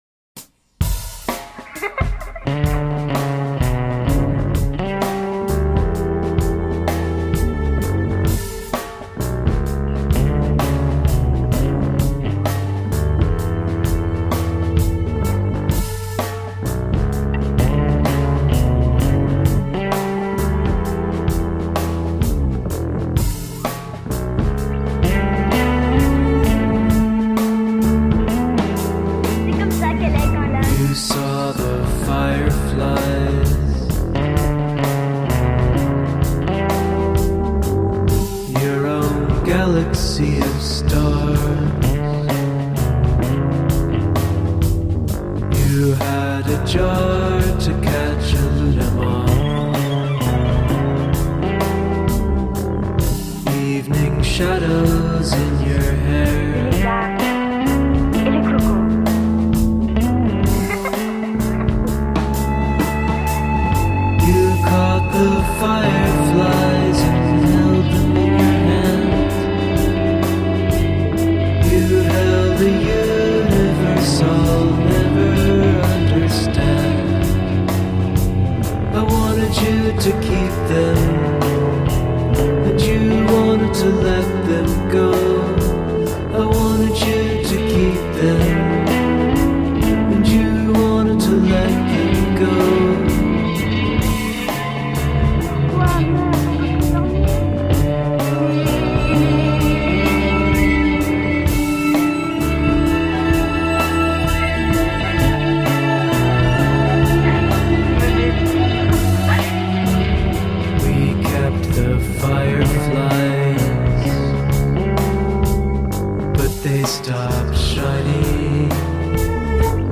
Cinematic pop-ambient.
Tagged as: Alt Rock, Rock, Ethereal, Prog Rock